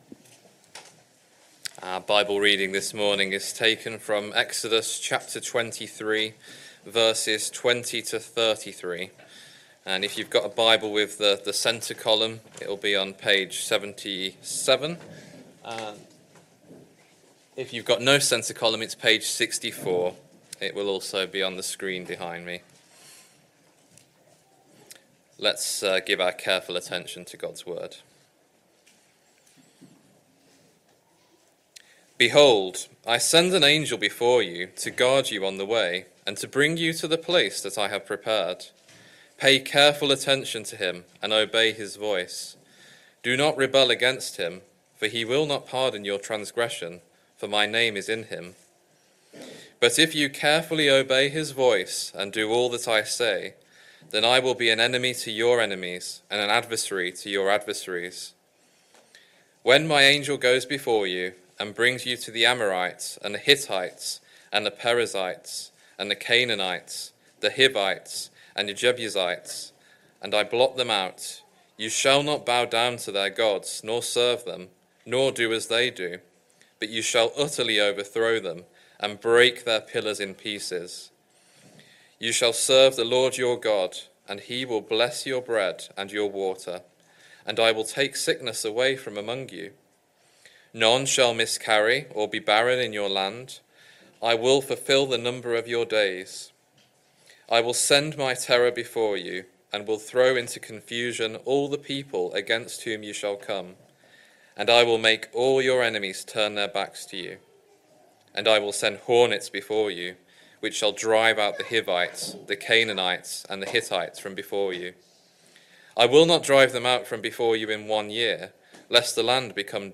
Sunday Morning Service Sunday 6th July 2025 Speaker